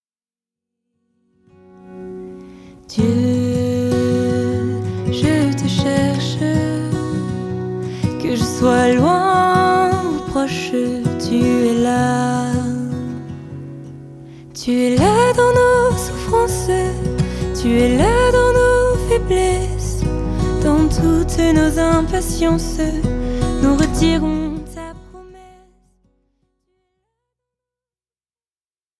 avec des mélodies apaisantes et une louange réconfortante.
ce sont 4 titres frais, doux et joyeux